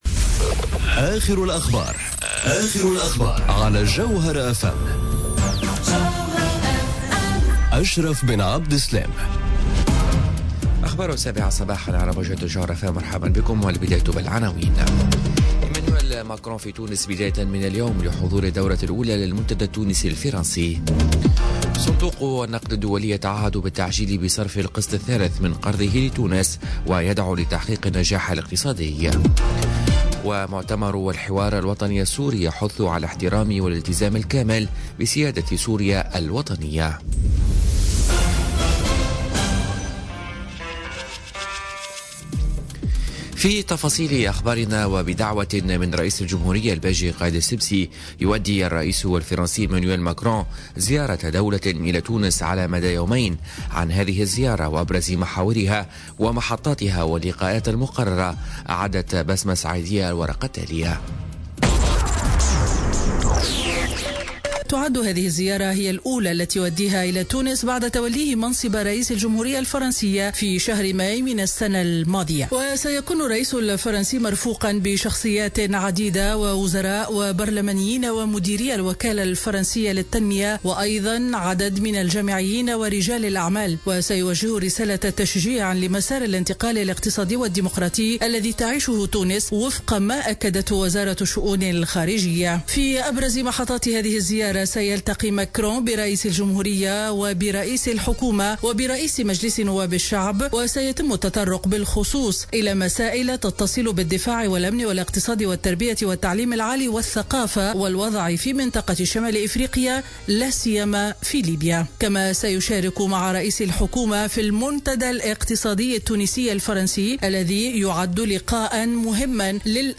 نشرة أخبار السابعة صباحا ليوم الأربعاء 31 جانفي 2018